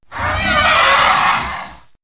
c_glizzom_hit1.wav